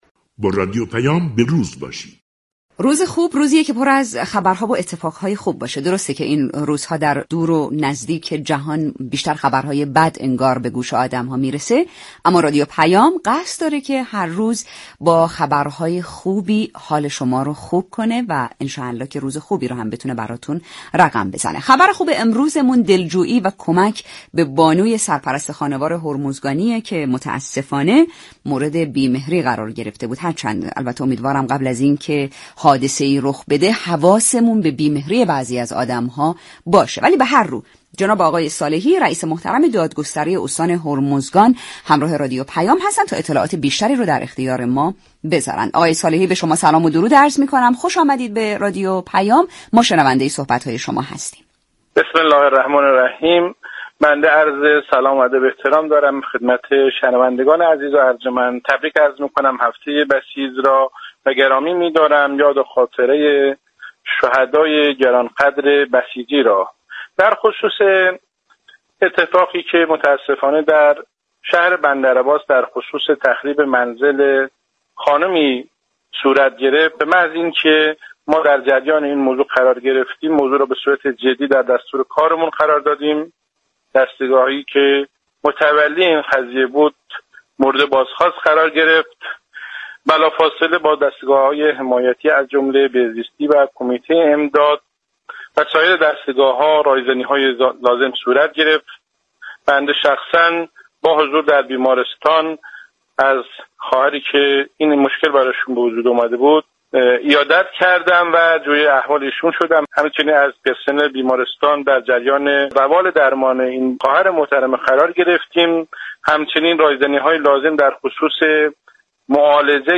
صالحی ،رئیس كل دادگستری استان هرمزگان در گفتگو با رادیو پیام ، اقدامات دستگاه قضا برای خانواده آسیب دیده حادثه تخریب در بندرعباس را بازگو كرد .